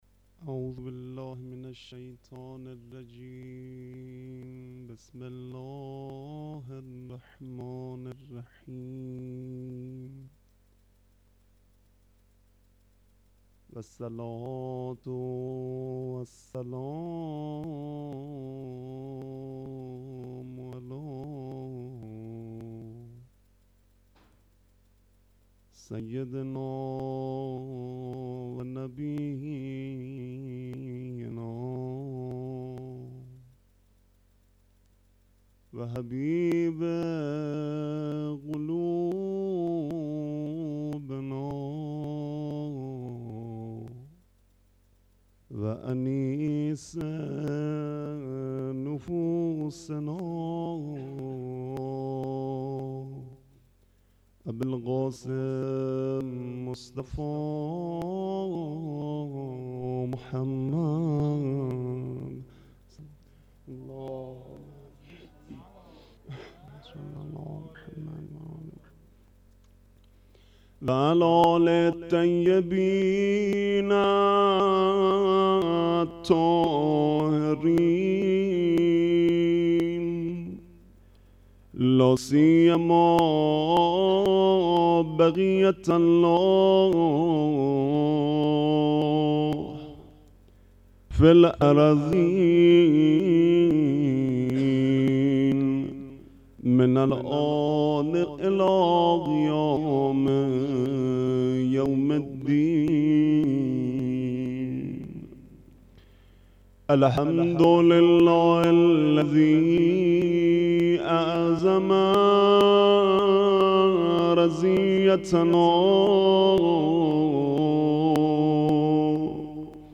sokhanrani.mp3